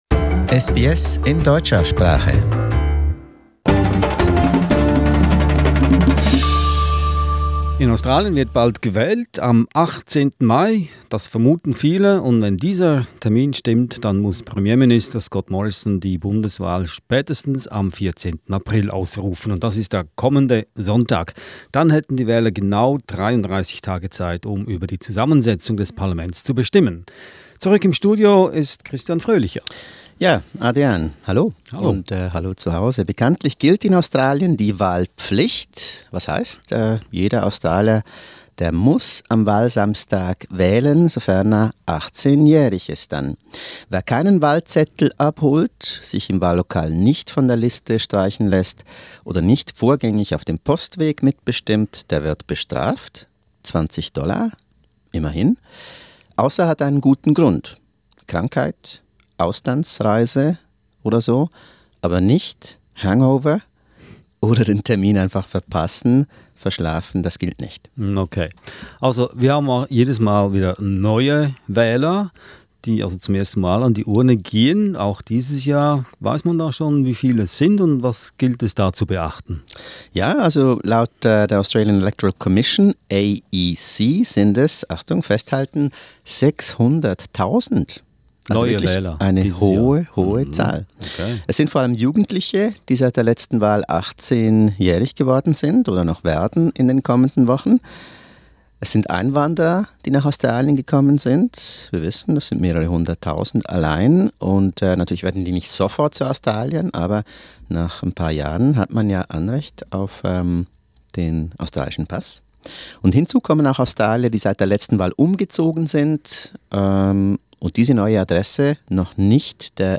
Wie man in Australien wählt: Ein deutsches Moderationsgespräch